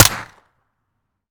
weap_uzulu_sup_fire_plr_01.ogg